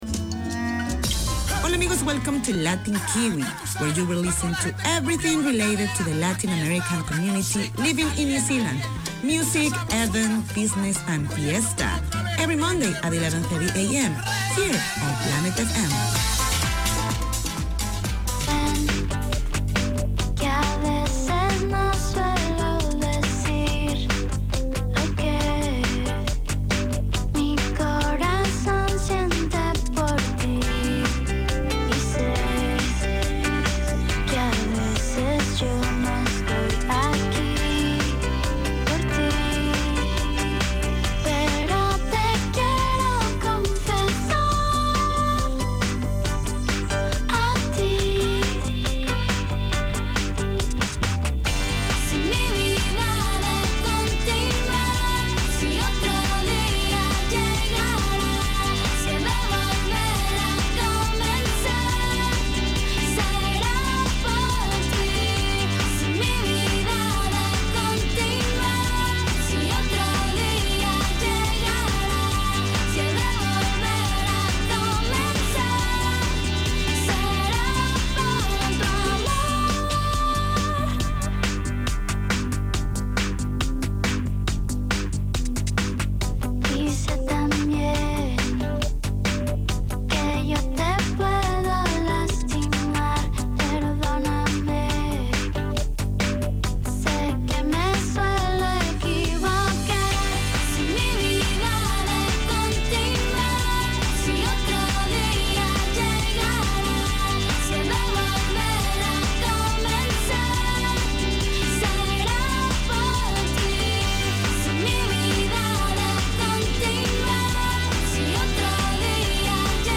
Radio made by over 100 Aucklanders addressing the diverse cultures and interests in 35 languages.
Latin Kiwi 4:25pm WEDNESDAY Community magazine Language: English Spanish Bienvenidos a todos!